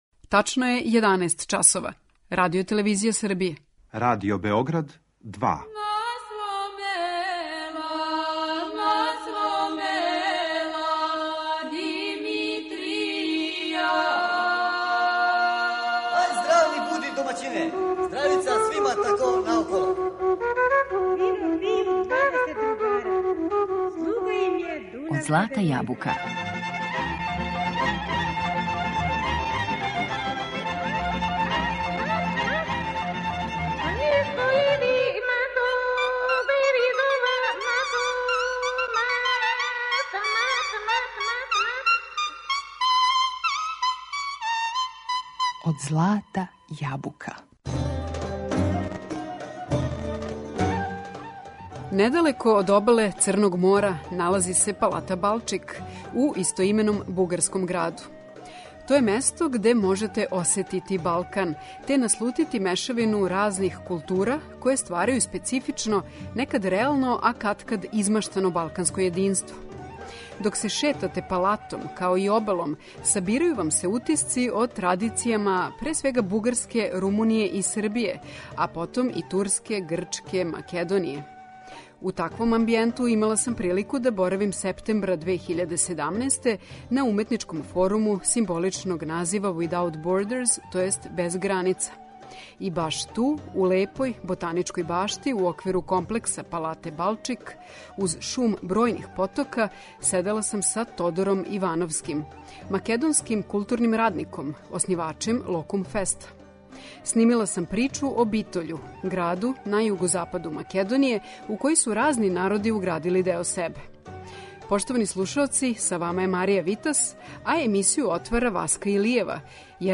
Боравак на арт форуму Without Borders био је прилика за интервју